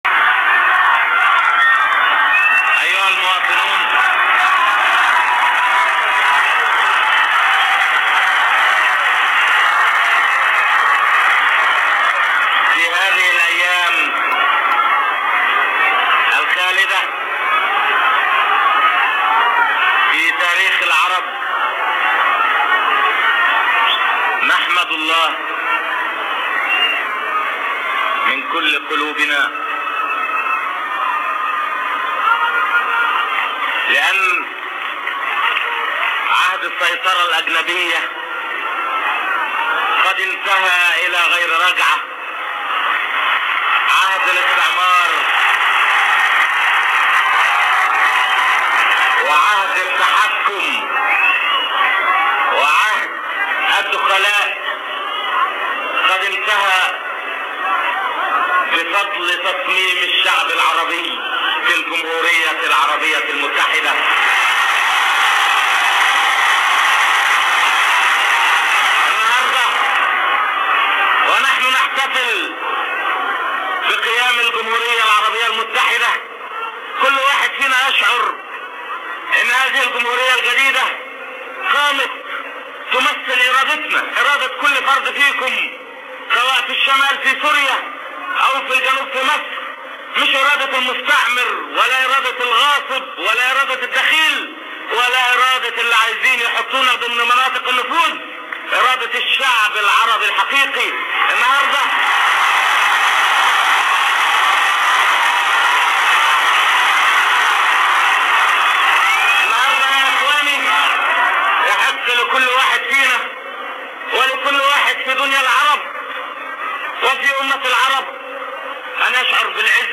나세르의 아랍 연합 공화국 선포, 1958년 2월 23일